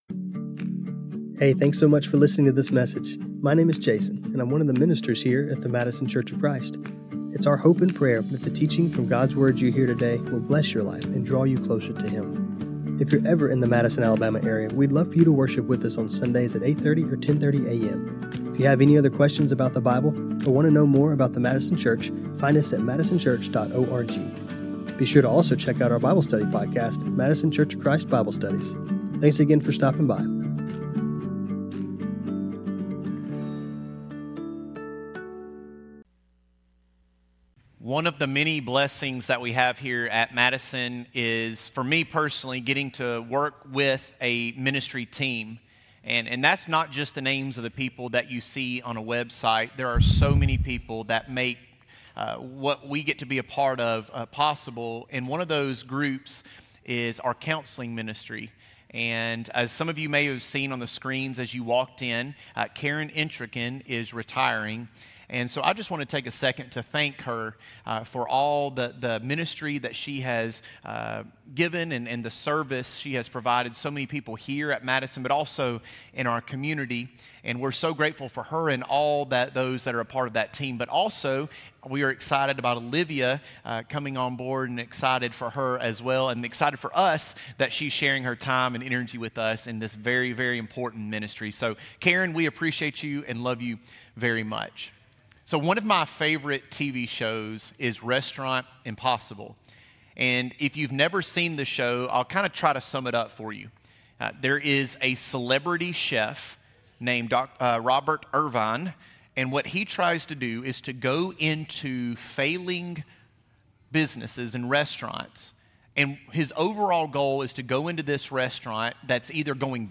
As we will see in our texts, spiritual disciplines may seem painful or restrictive at first, but they are designed for transformation rather than mere information. Today we will end our series by discovering how to move from a life of exhausting, chaotic routine to a structured, flourishing life by practicing the way of Jesus. This sermon was recorded on Apr 19, 2026.